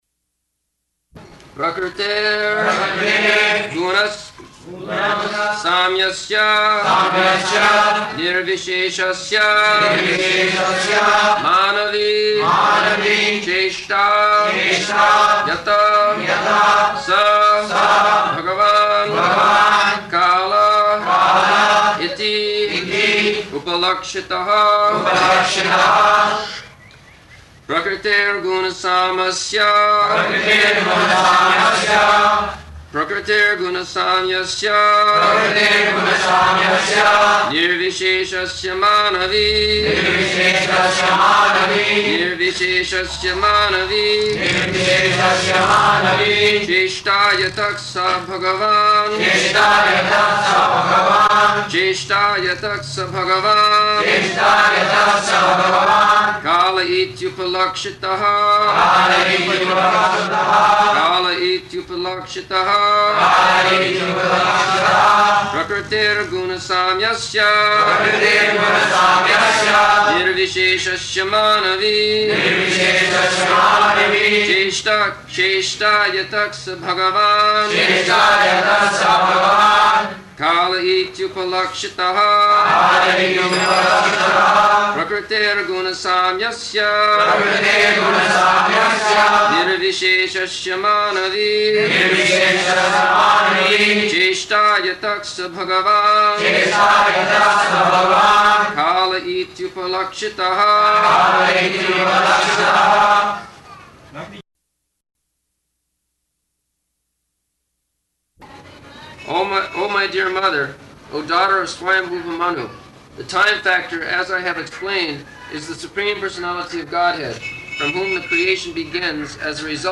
-- Type: Srimad-Bhagavatam Dated: December 26th 1974 Location: Bombay Audio file
[leads chanting of verse, etc.] [devotees repeat]